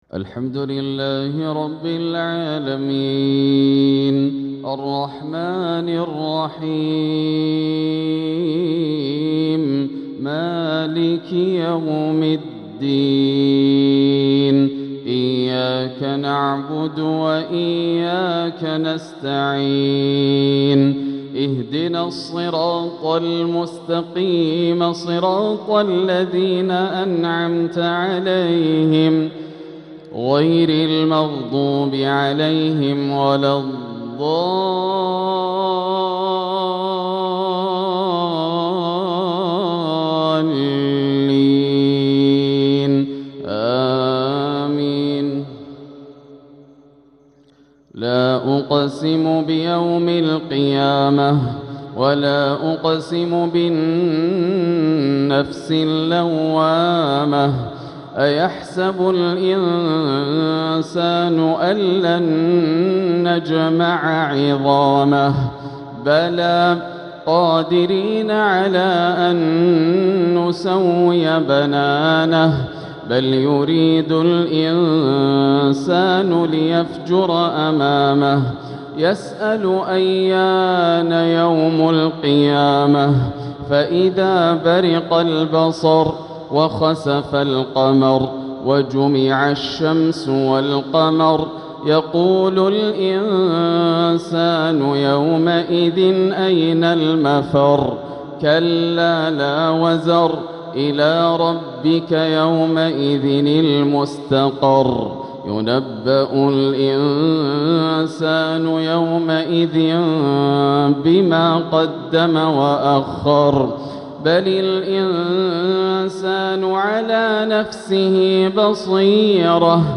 تلاوة لسورة القيامة عشاء الثلاثاء 4-2-1447 > عام 1447 > الفروض - تلاوات ياسر الدوسري